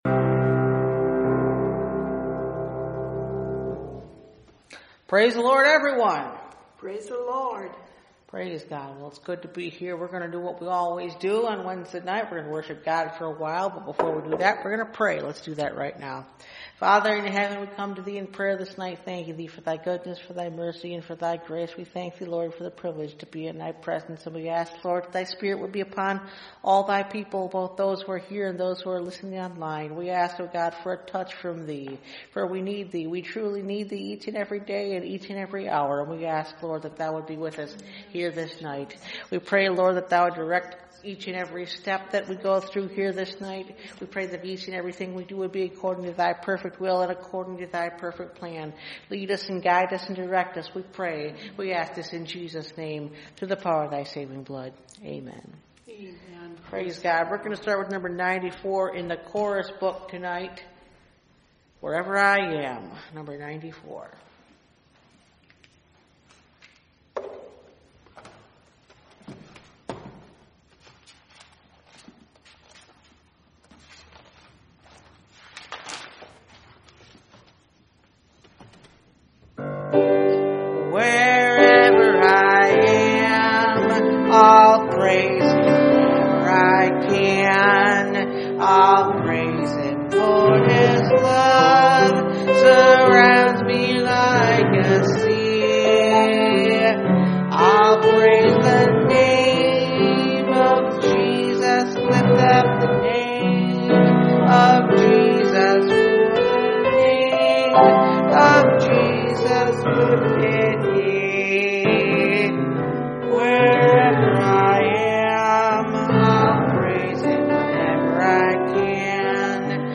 Service Type: Wednesday Night Bible Study